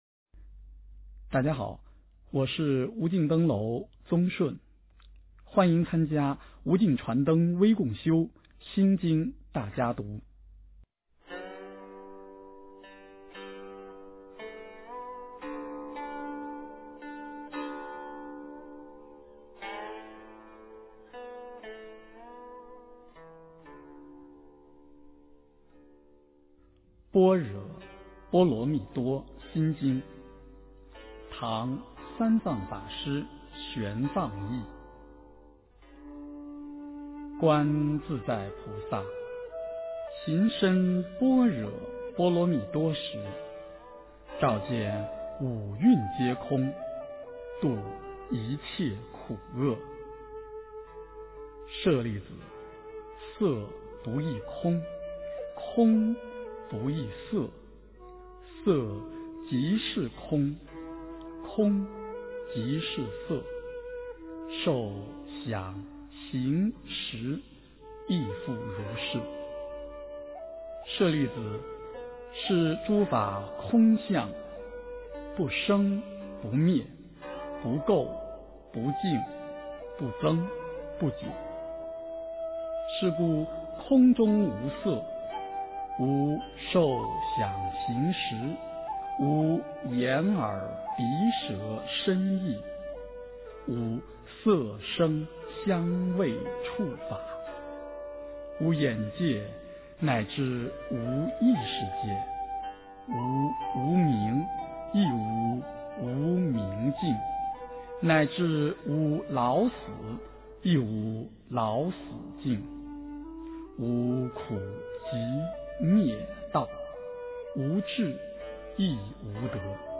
心经-念诵
诵经